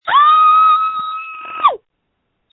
• When you call, we record you making sounds. Hopefully screaming.
You might be unhappy, terrified, frustrated, or elated. All of these are perfectly good reasons to call and record yourself screaming.